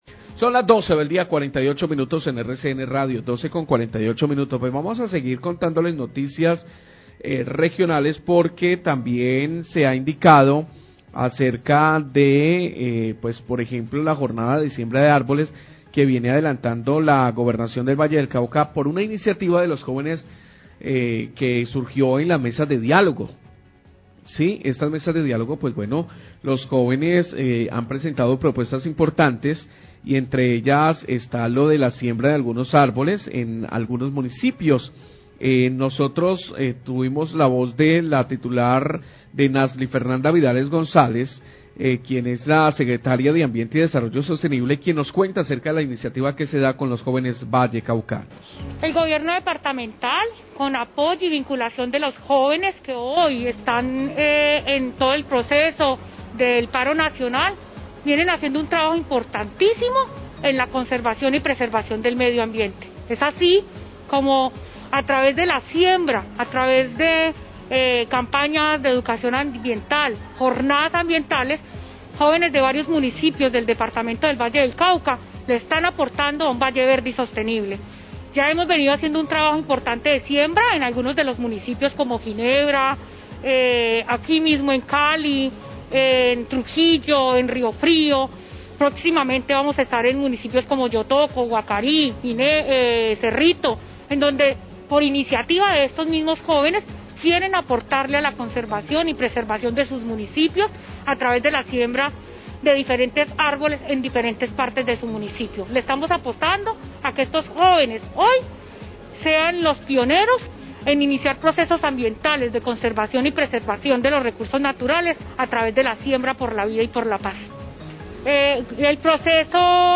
Radio
La Gobernación del Valle aportó las especies forestales y asesorías para que jóvenes participantes del paro nacional siembren estas plántulas en sus localidades como parte de los acuerdos que se alcanzaron. Declaraciones de Nasly Fernanda Vidales, Secretaria de Ambiente y desarrollo Sostenible.